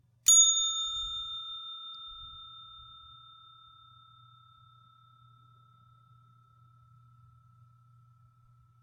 service bell ring
bell butler cashier checkstand counter desk ding hotel sound effect free sound royalty free Sound Effects